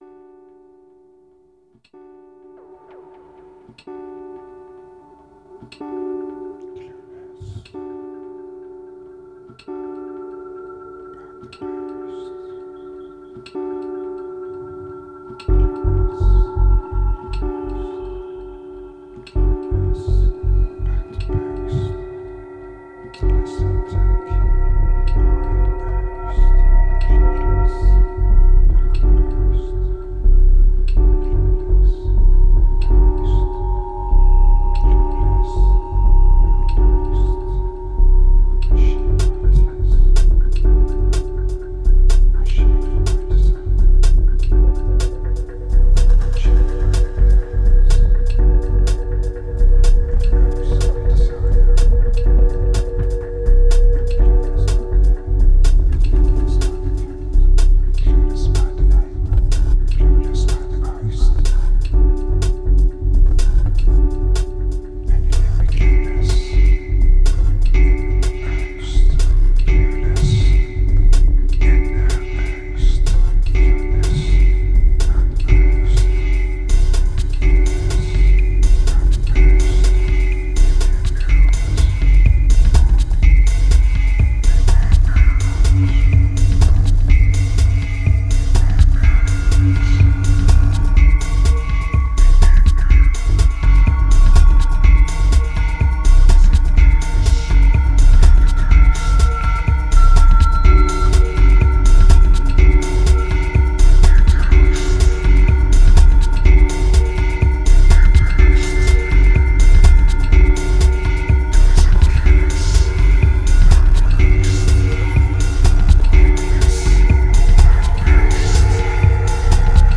2122📈 - -25%🤔 - 124BPM🔊 - 2010-11-17📅 - -598🌟